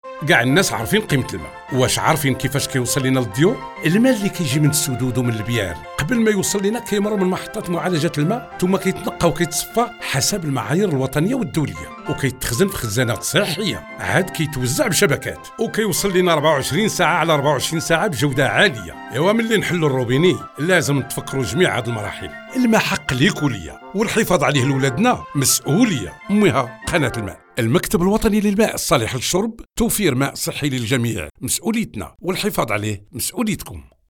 Spots radio: